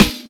sgsi_snr.wav